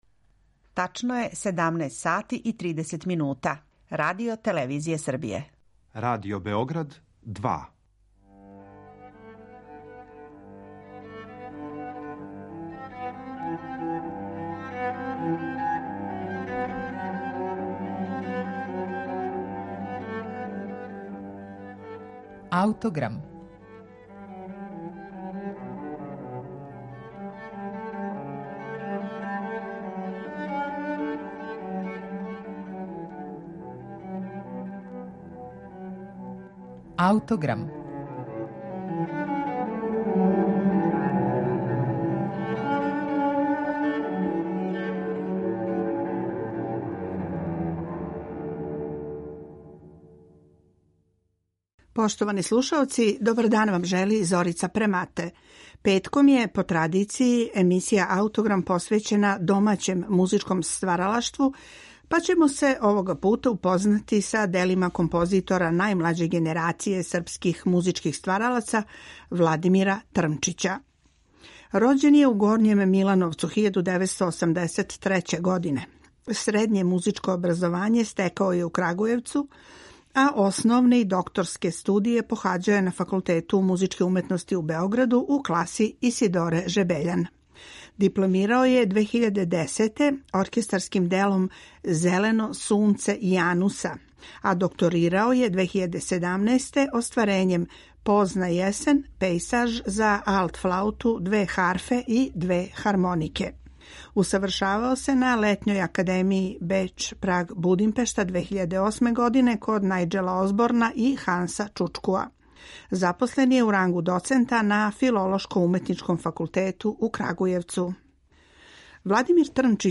оркестарско остварење
са снимка оствареног на премијерном извођењу